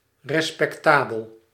Ääntäminen
IPA : [ɹi.ˈspɛk.tə.bl̩]